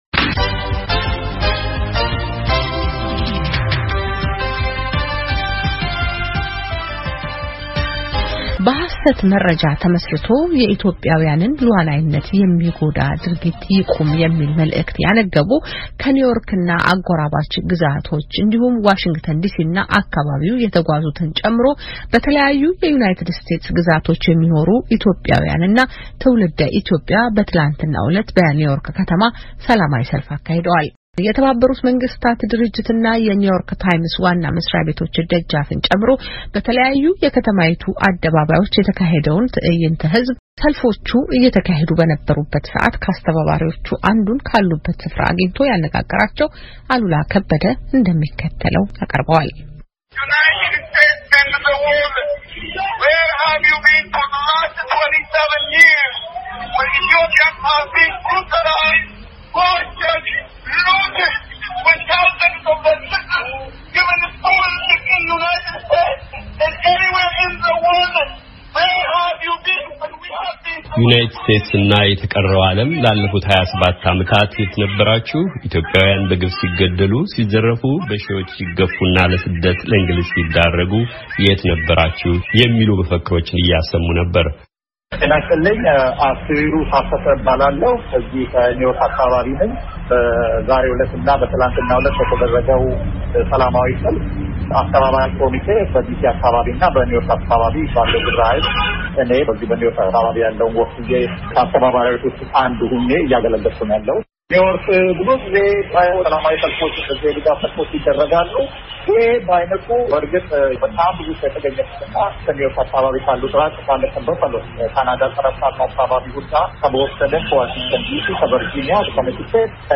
በኒው ዮርክ ከተማ የሚገኙትን የተባበሩት መንግስታት ድርጅት እና የኒው ዮርክ ታይምስ ዋና መሥሪያ ቤቶች ደጃፍ ጨምሮ በተለያዩ የከተማይቱ አደባባዮች ስለተካሄደው ትዕይንተ-ሕዝብ ካስተባበሪዎቹ አንዱን ሰልፎቹ እየተካሄዱ በነበረበት ሰዓት ካሉበት ስፍራ በስልክ አነጋግረናል።